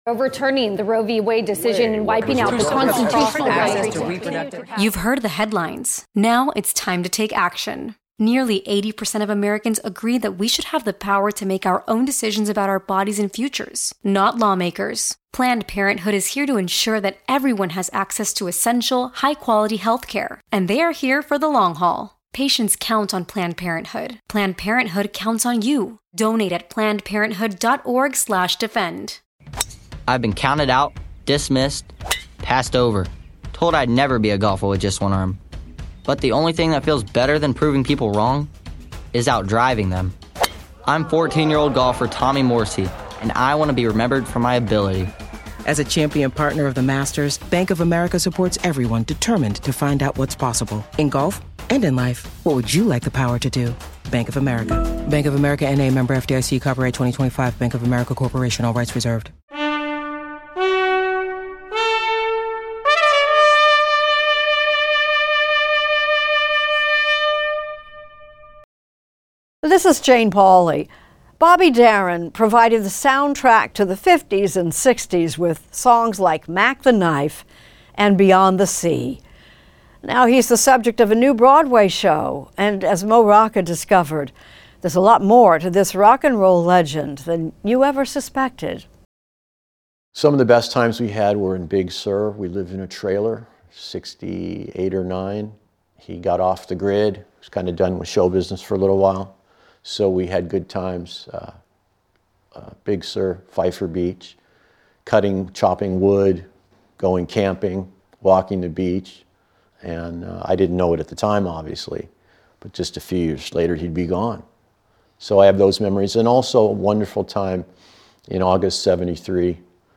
Extended Interview